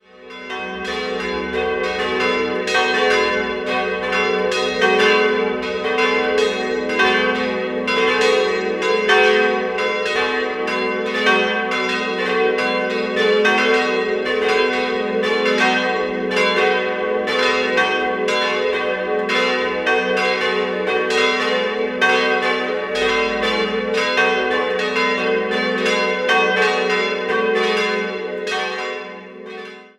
In den Jahren 1881/82 erfolgten ein Neubau des Turms sowie eine Verlängerung des Langhauses. 4-stimmiges Geläute: g'-a'-c''-e'' Die kleinste Glocke stammt vermutlich noch aus dem 14. Jahrhundert, die drei anderen goss im Jahr 1951 Friedrich Wilhelm Schilling in Heidelberg.